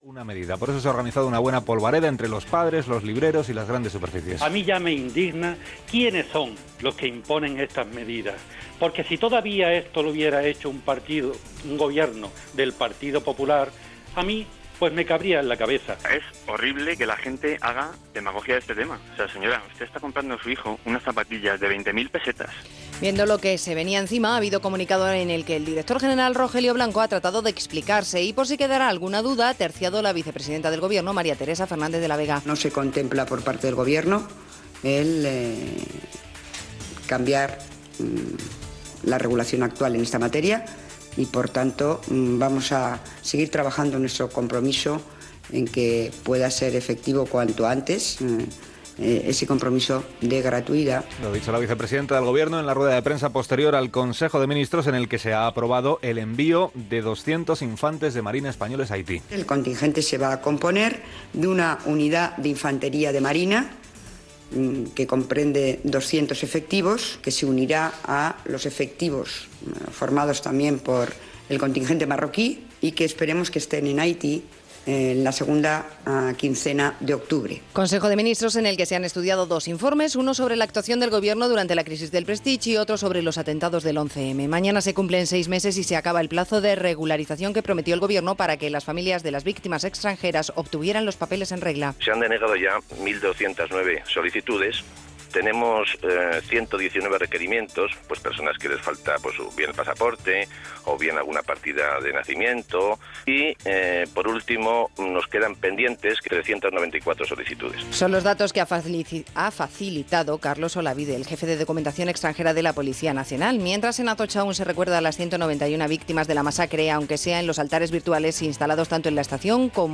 Informacions del consell de ministres espanyol, l'enviament d'efectius militars espanyols a Haití, la inlació dels preus, les movilitzacions als astillers i l'evaquació d'una pastera. Hora, publicitat i indicatiu de la cadena a Catalunya
Informatiu
FM